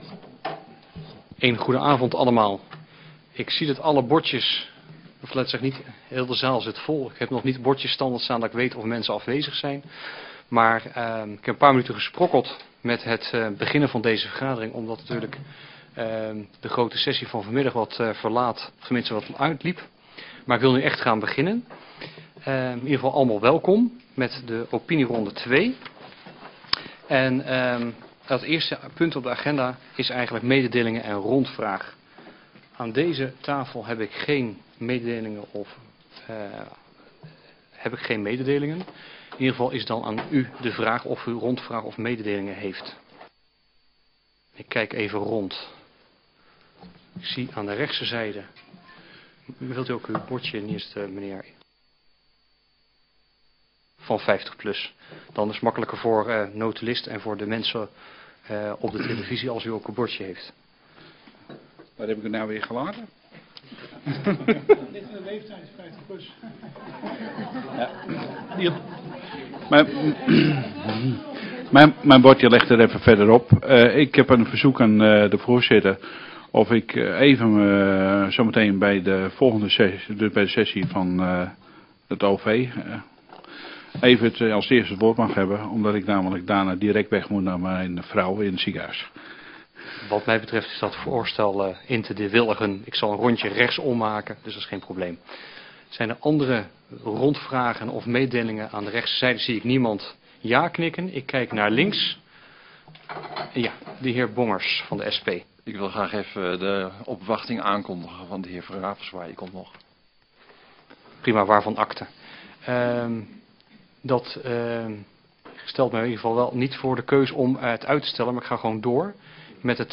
Download de volledige audio van deze vergadering
Locatie: Statenzaal